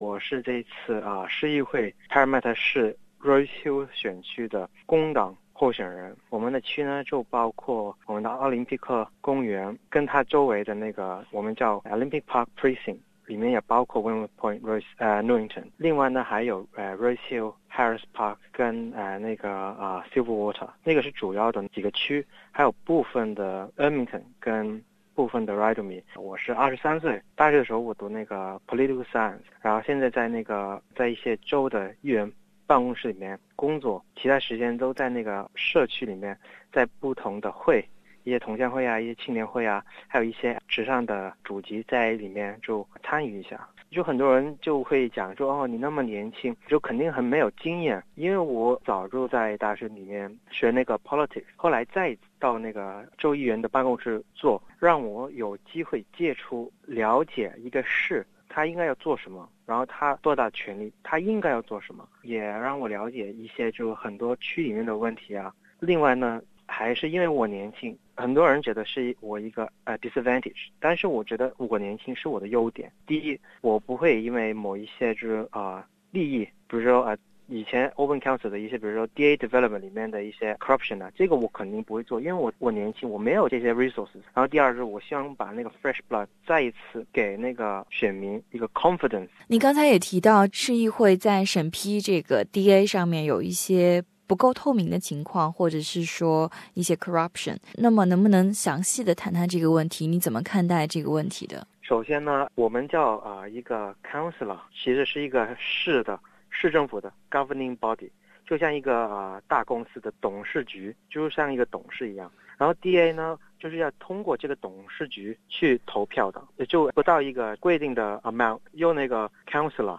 在接受本台记者采访中，他同时也批露了市政府在DA土地开发审批上的腐败和不透明 。